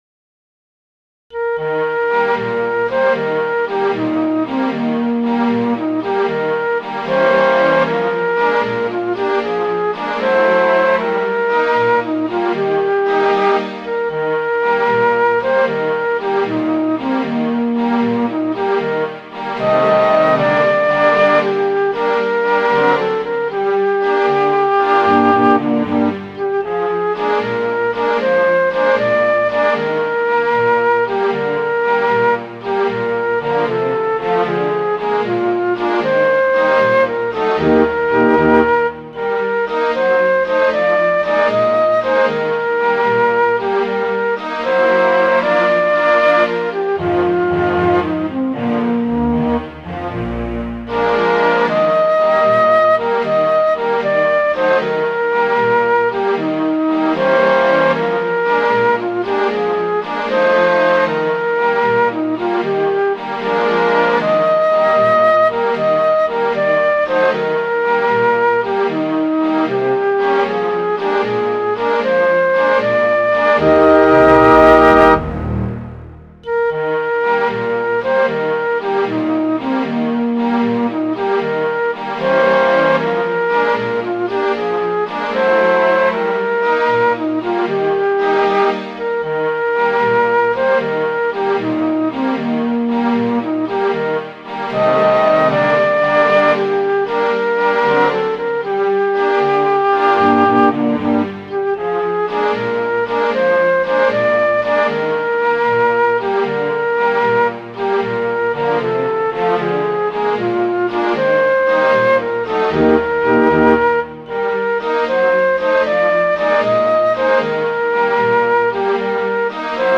Midi File, Lyrics and Information to Nancy Lee